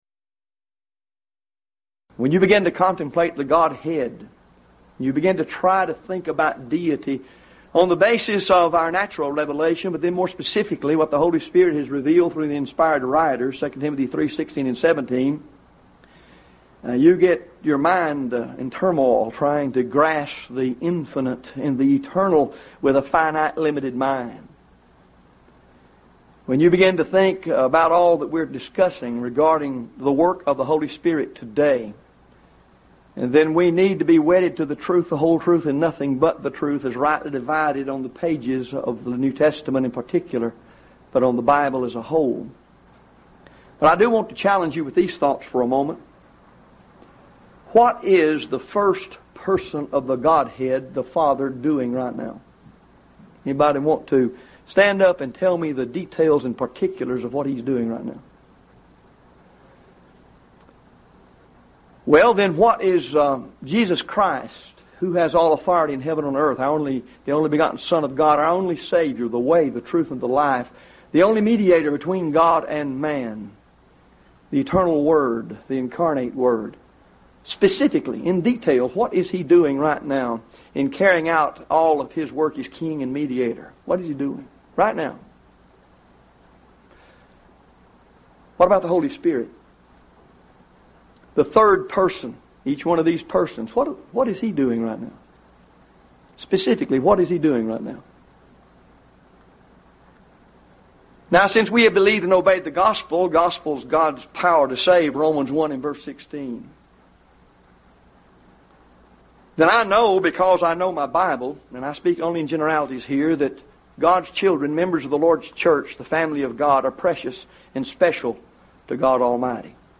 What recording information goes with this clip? Event: 1st Annual Lubbock Lectures